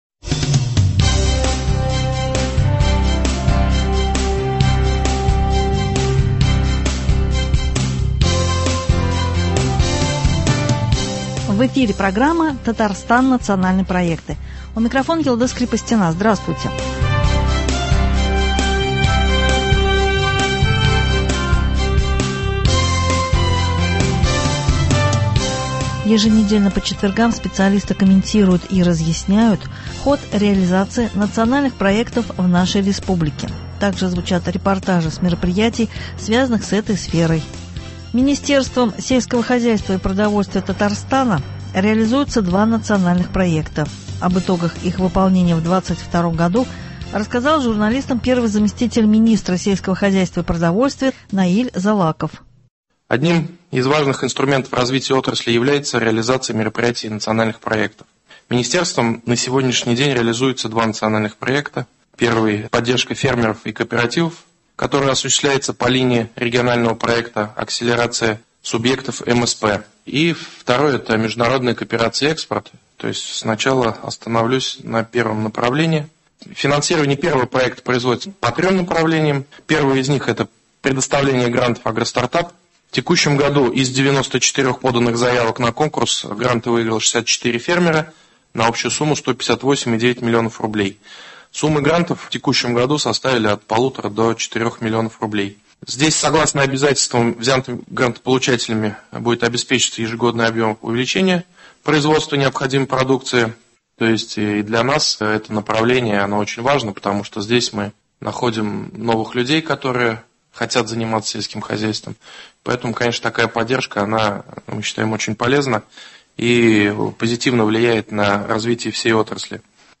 Еженедельно по четвергам специалисты комментируют и разъясняют ход реализации Национальных проектов в нашей республике. Также звучат репортажи с мероприятий, связанных с этой сферой.